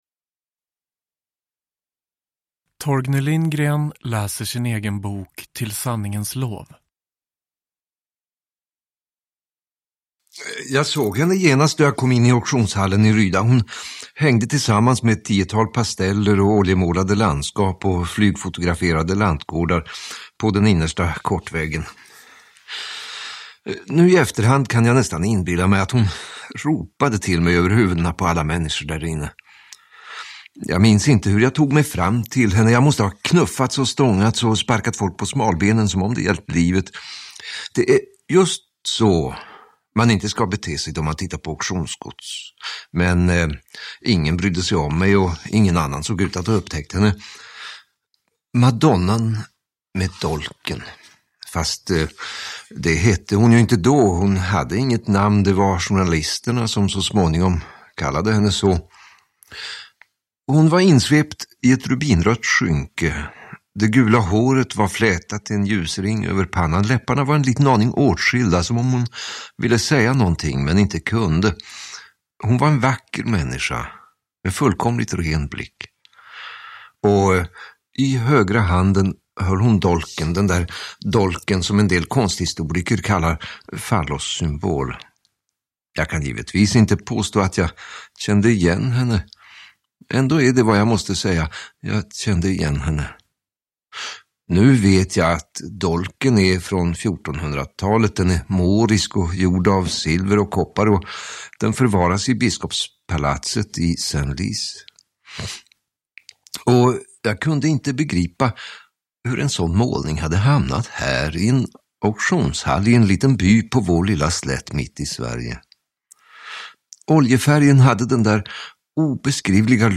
Till sanningens lov : rammakaren Theodor Marklunds egen redogörelse – Ljudbok – Laddas ner
Uppläsare: Torgny Lindgren